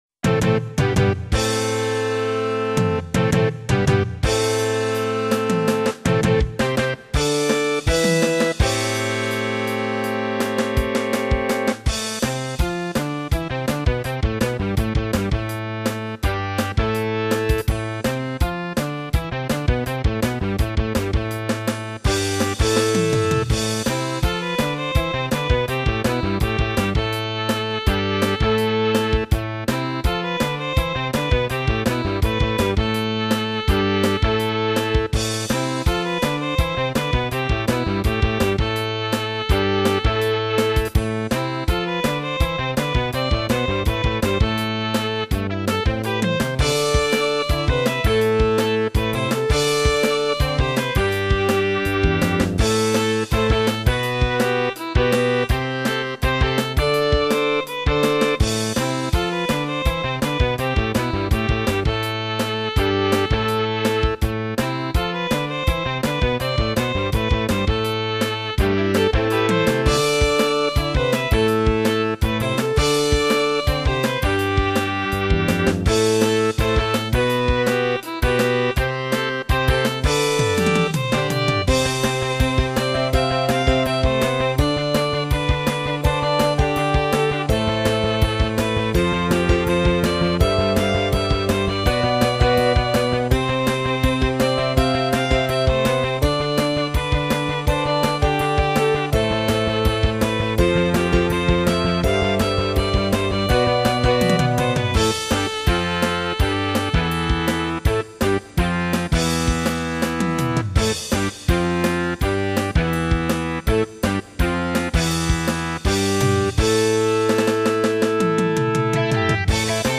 大空を元気いっぱい飛行する小型飛行機を操る少年をイメージして作りました。スピード感のある元気な曲を作ったつもりです。